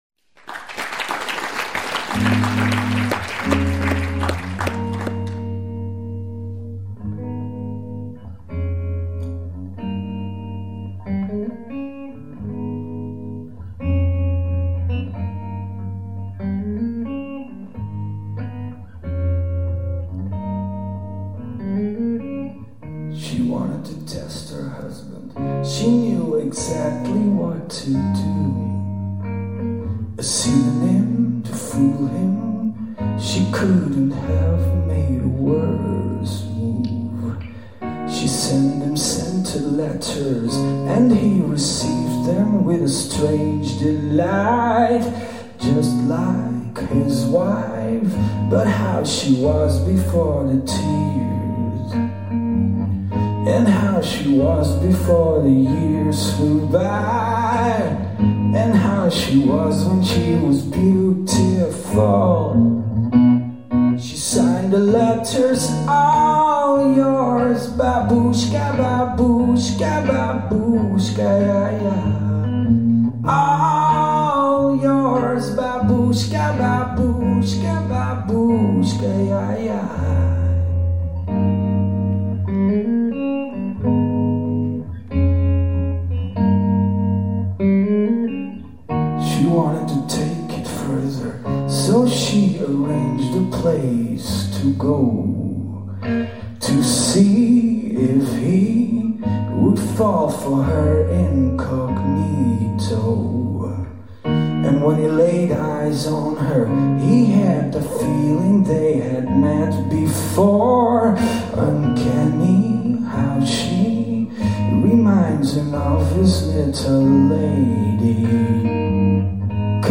live Geletterde Mensen, Berchem 2004